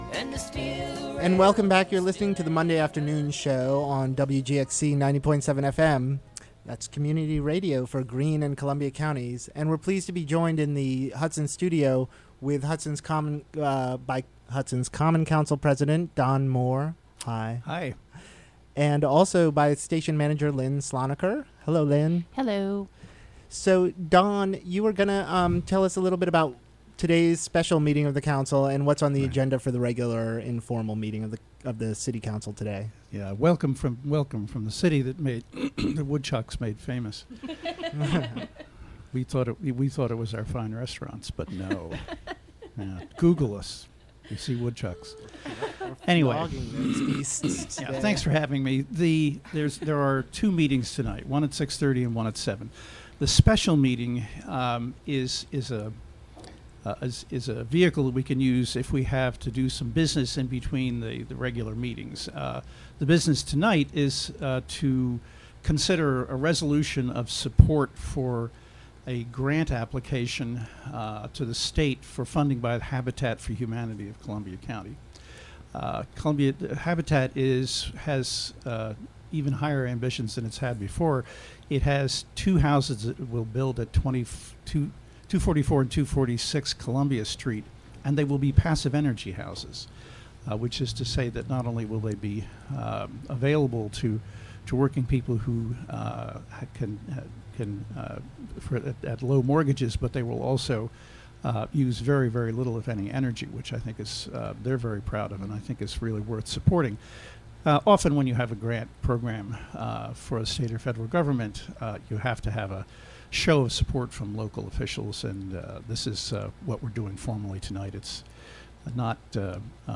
Hudson Common Council President Don Moore discusses city issues.